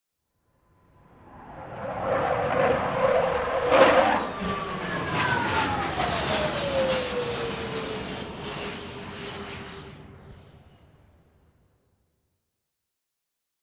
Airplane Avro Vulcan overhead engine doppler jet